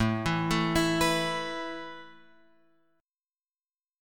A Suspended 2nd